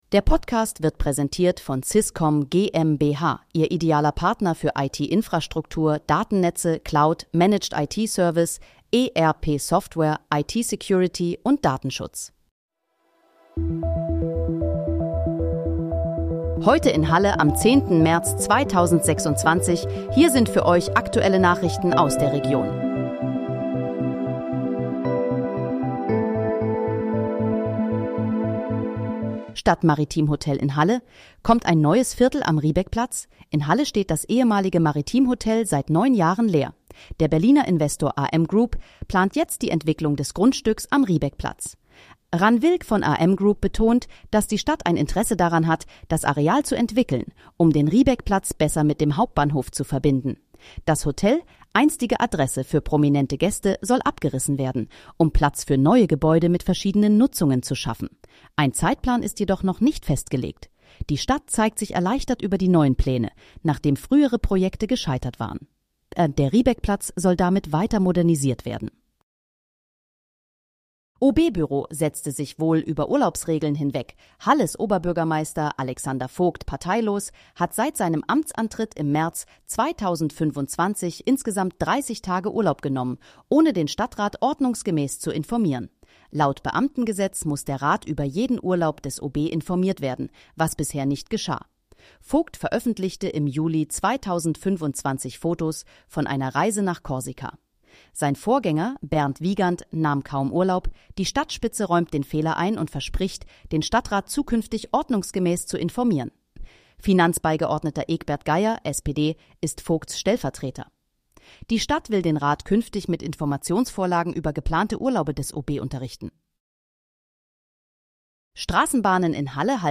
Heute in, Halle: Aktuelle Nachrichten vom 10.03.2026, erstellt mit KI-Unterstützung
Nachrichten